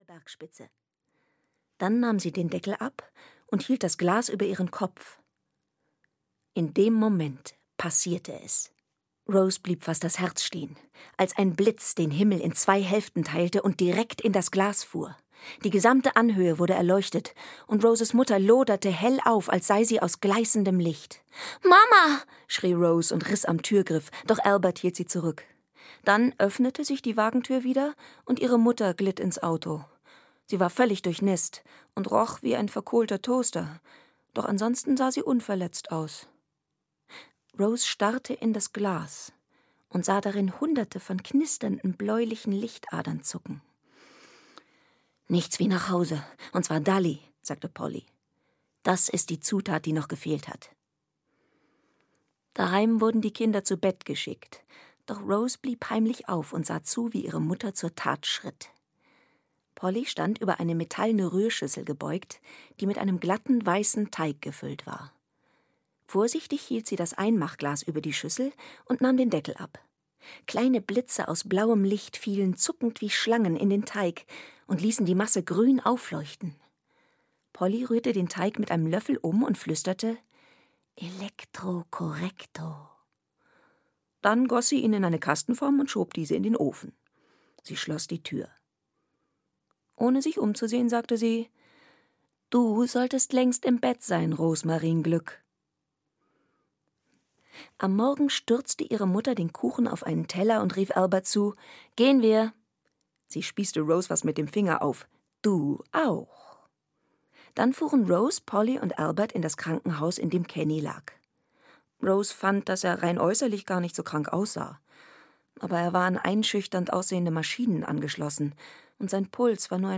Die Glücksbäckerei 1: Das magische Rezeptbuch - Kathryn Littlewood - Hörbuch